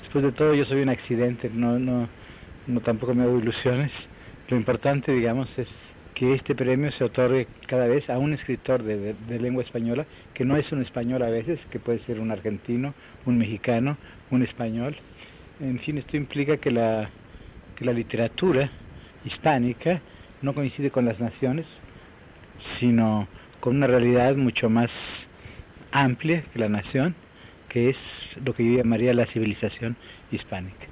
Declaració de l'escriptor Octavio Paz, a Madrid, en la seva arribada a Madrid per recollir el Premio Miguel de Cervantes 1981 (que es va lliurar el 23 d'abril del 1982)